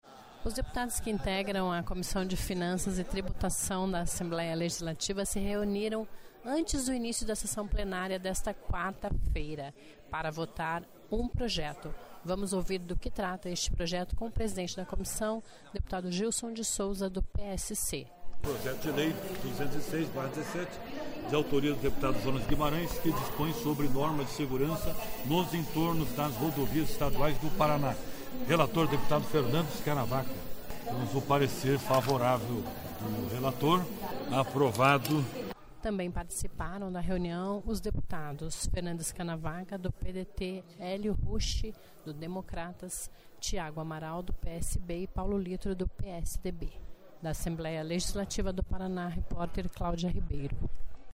Ouça na íntegra a entervista com deputado Gílson de Souza (PSC), presidente da Comissão.